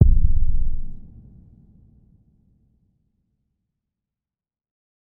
Muffled Distant Explosion